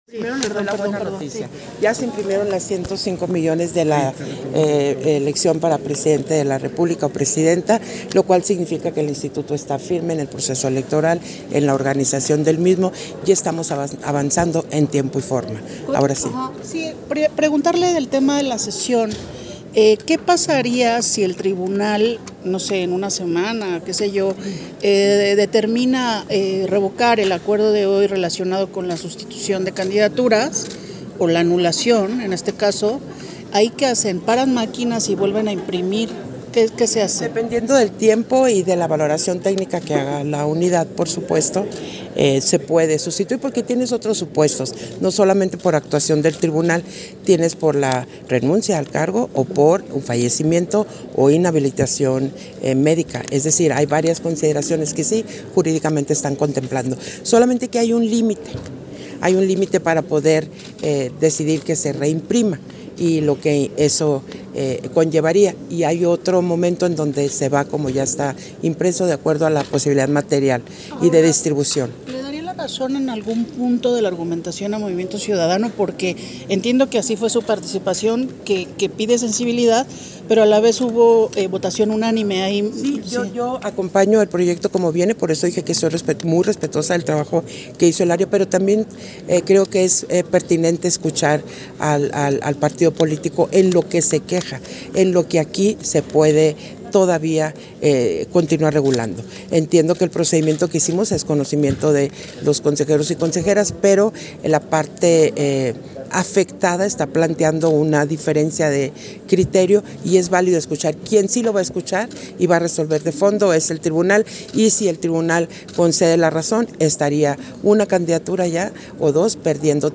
Entrevista a la Consejera Presidenta, Guadalupe Taddei, al concluir la Sesión Extraordinaria del Consejo General